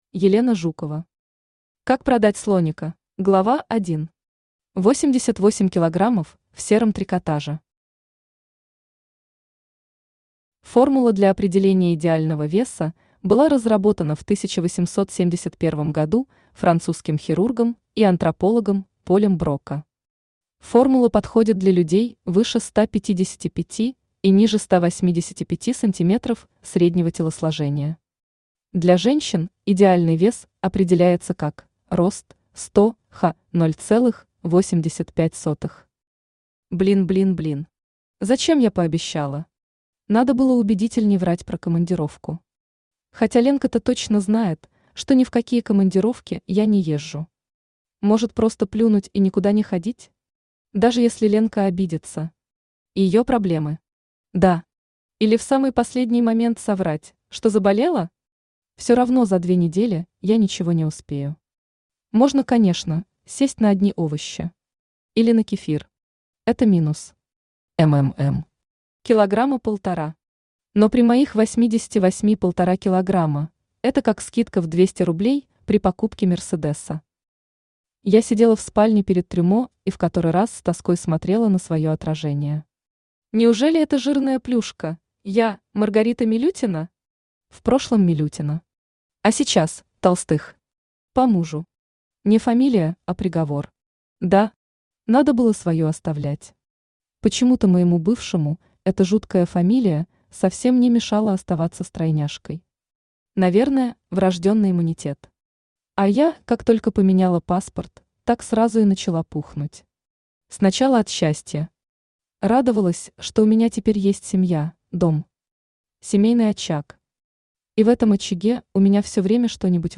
Аудиокнига Как продать слоника?
Автор Елена Жукова Читает аудиокнигу Авточтец ЛитРес.